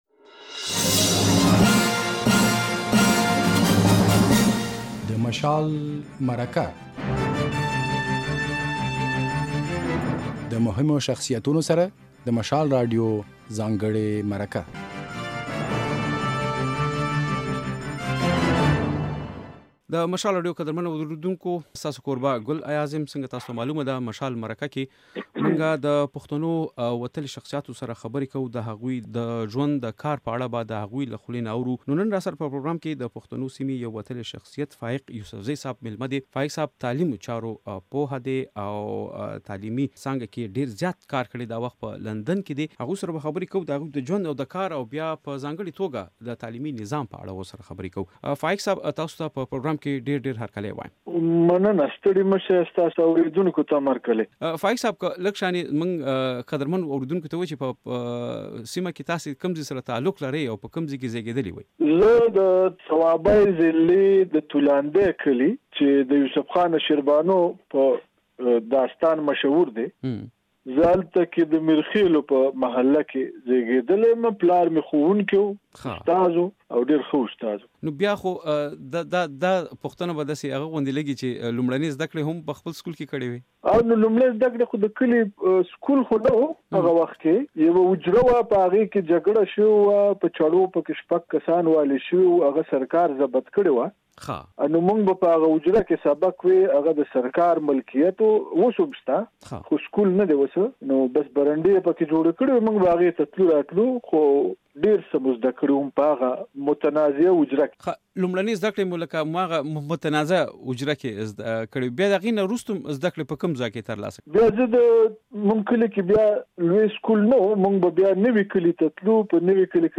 له نوموړي سره بشپړه مرکه د غږ په ځای کې واورئ.